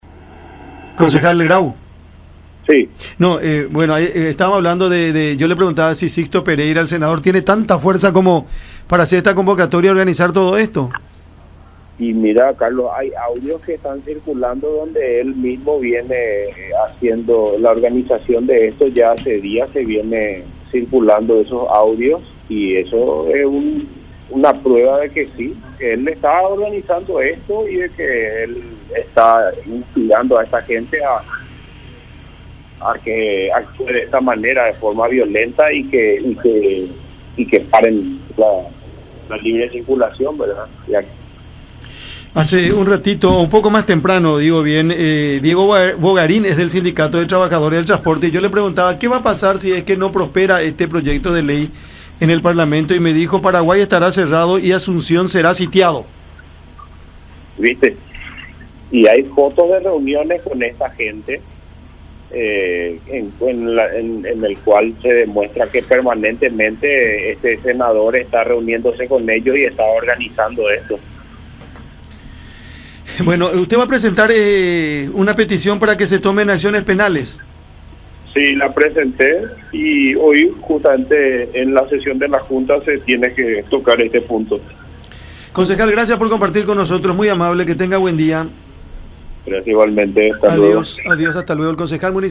“Estoy presentando un pedido para que el intendente accione penalmente contra los camioneros que cierran el acceso a la ciudad de Asunción. Se está violando el derecho de libre circulación de las personas”, dijo Grau en conversación con Cada Mañana a través de La Unión.